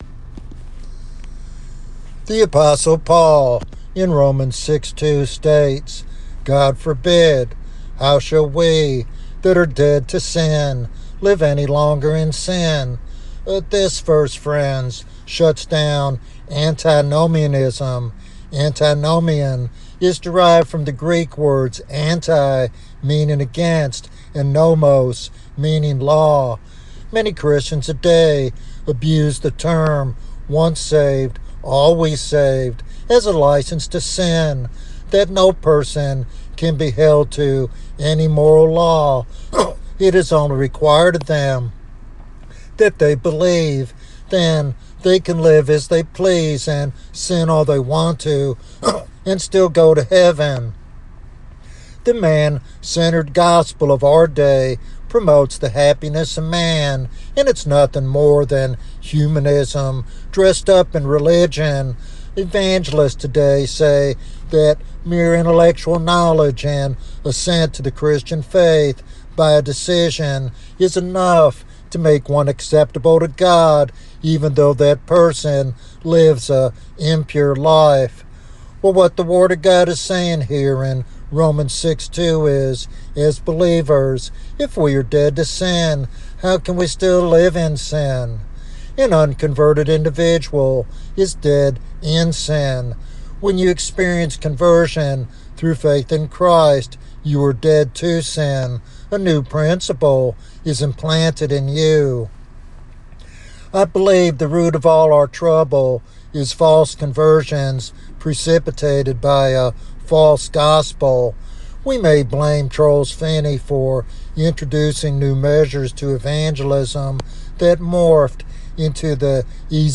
This sermon is a passionate call to rediscover the gospel of the cross and live a victorious Christian life founded on faith and surrender to Jesus Christ.